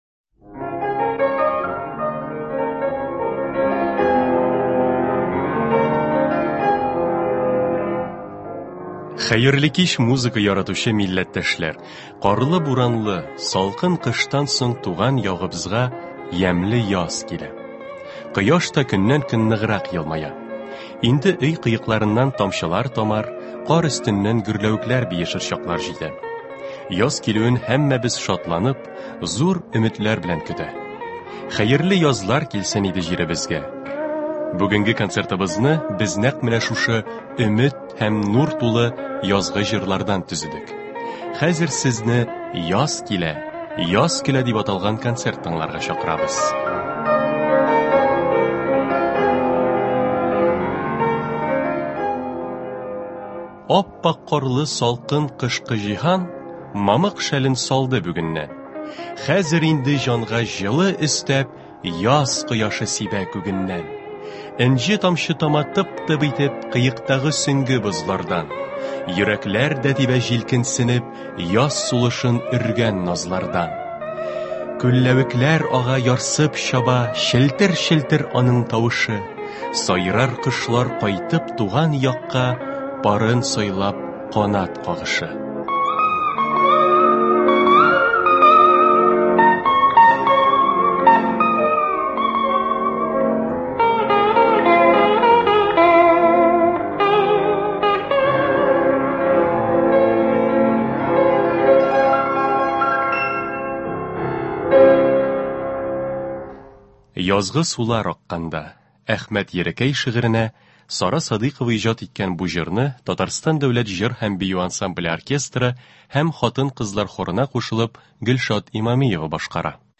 Көндезге концерт.
Кичке концерт.